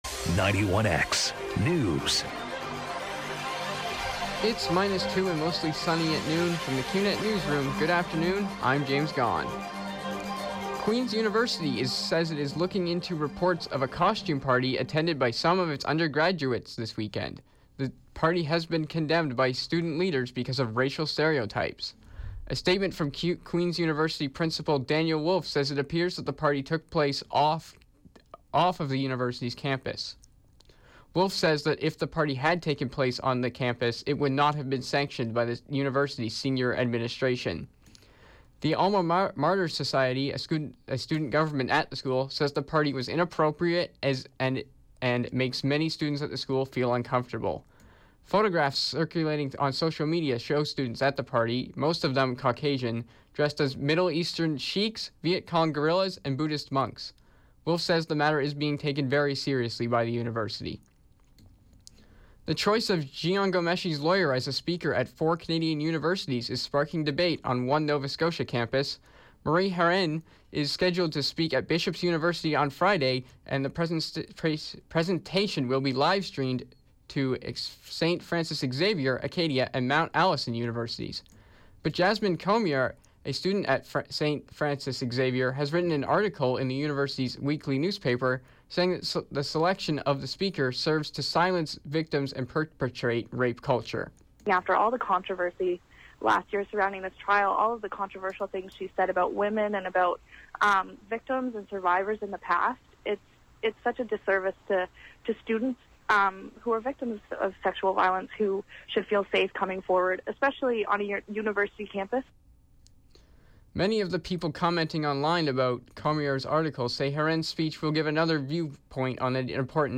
91X Newscast- Wednesday, Nov. 23, 2016, 12 p.m.